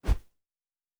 pgs/Assets/Audio/Fantasy Interface Sounds/Whoosh 07.wav
Whoosh 07.wav